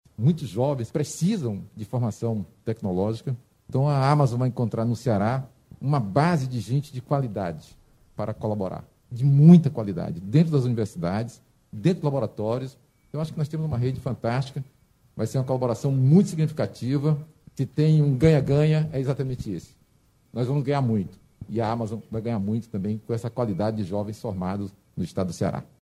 O secretário da Ciência e Tecnologia, Inácio Arruda destacou que o projeto vai trazer ganhos para ambos os lados, pois o Ceará também oferece mão-de-obra especializada.